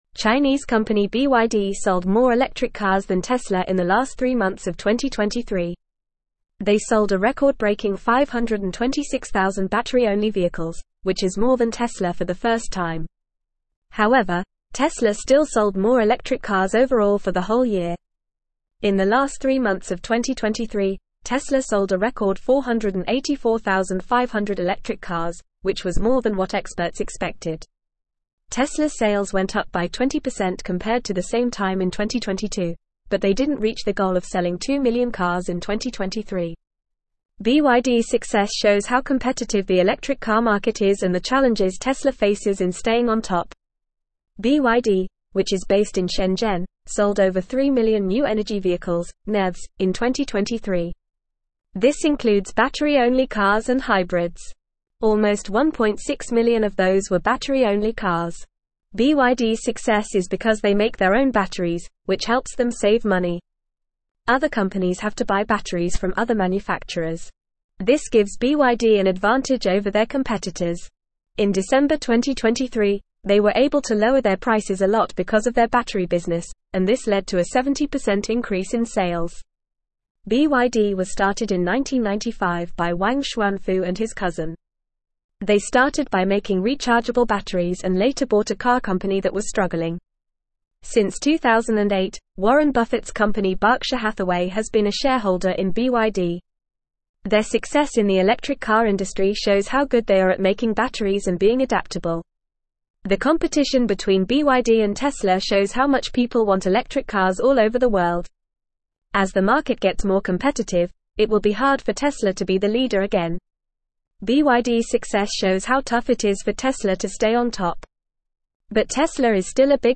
Fast
English-Newsroom-Upper-Intermediate-FAST-Reading-BYD-Overtakes-Tesla-in-Electric-Vehicle-Sales.mp3